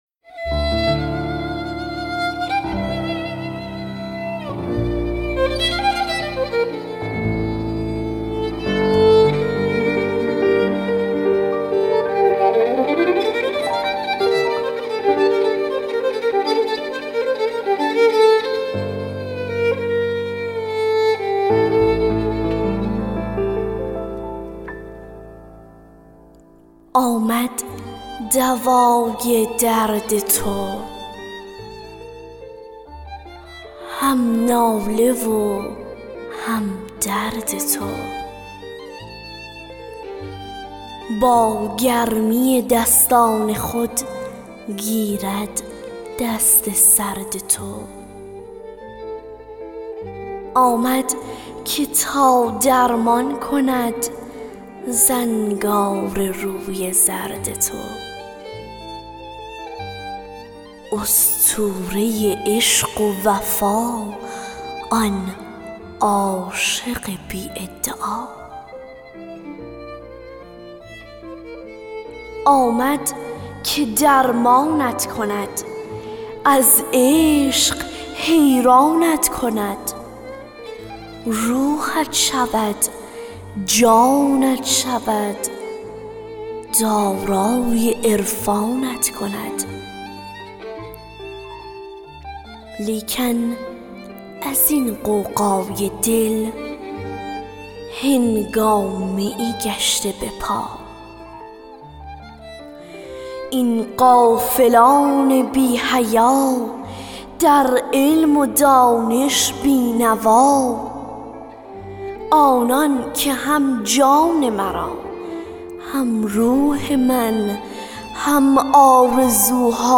دکلمه اشعار همراه با موسیقی